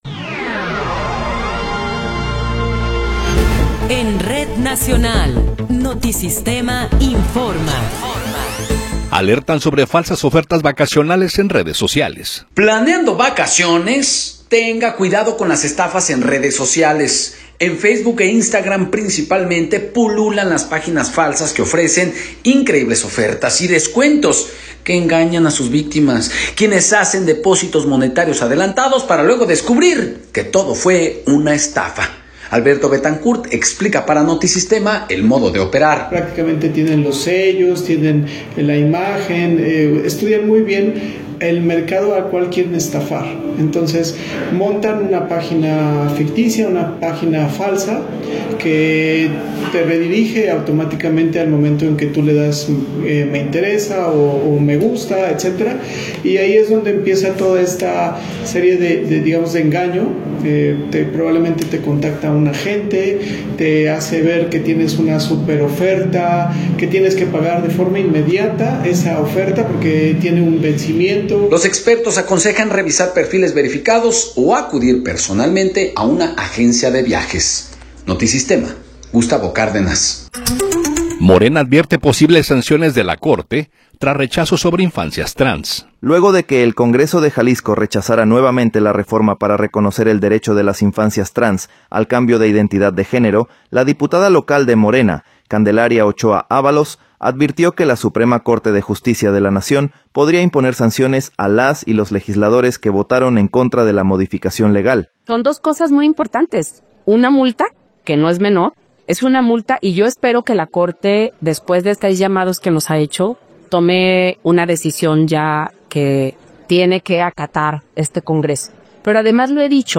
Noticiero 16 hrs. – 29 de Enero de 2026
Resumen informativo Notisistema, la mejor y más completa información cada hora en la hora.